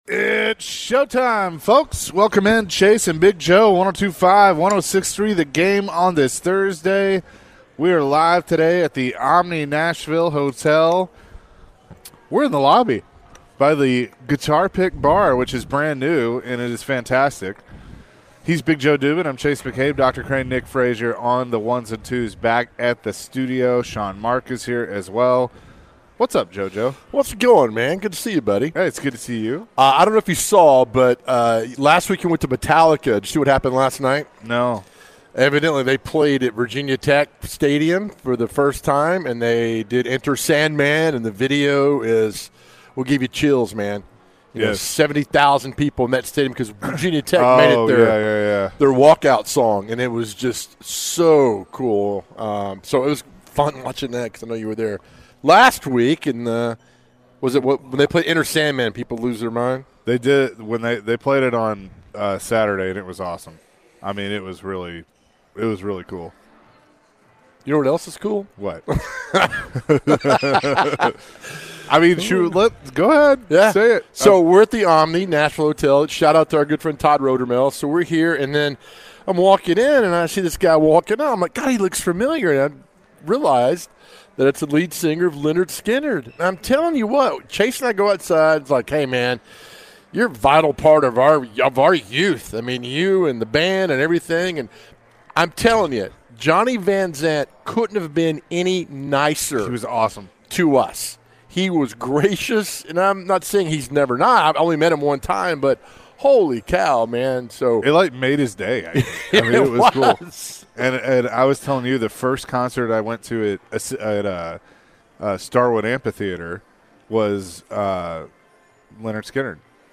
What was the most important decision you made in your life? Later in the hour, the guys answered some phones surrounding the question.